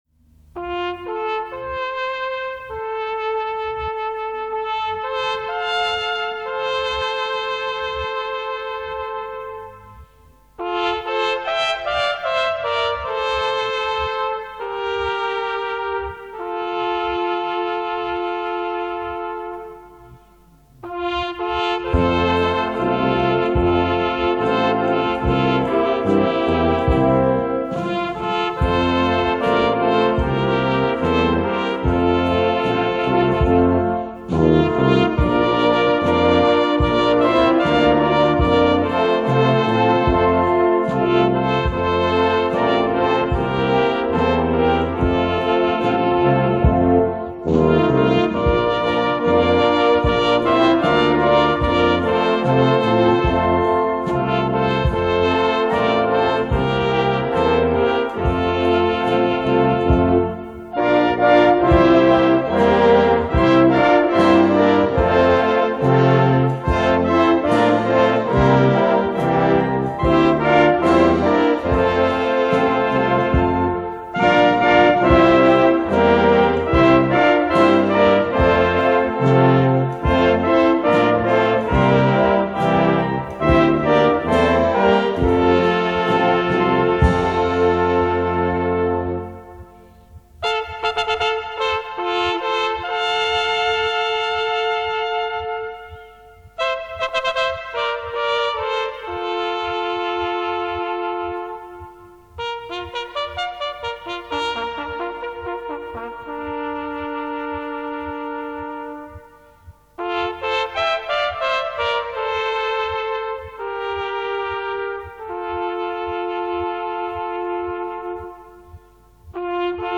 Volkslied für 2 Solo-Trompeten, Orchester